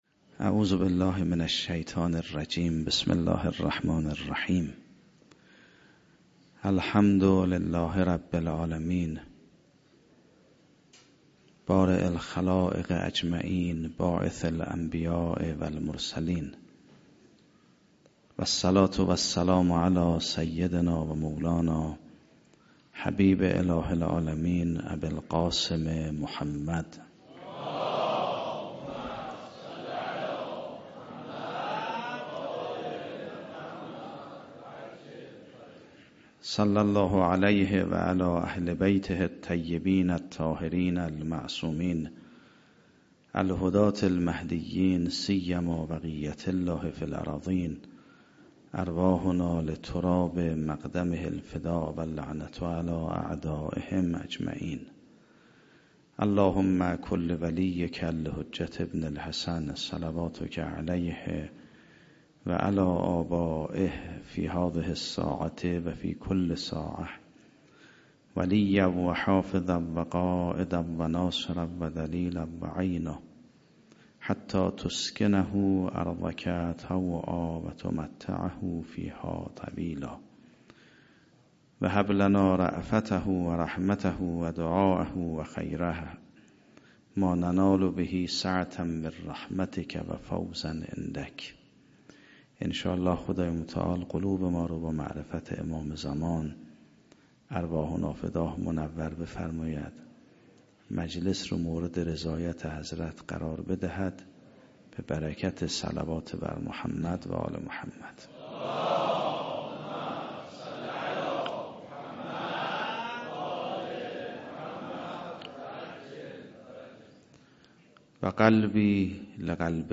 گزارش صوتی روز ولادت حضرت اباالفضل(ع) در حسینیه آیت الله حق شناس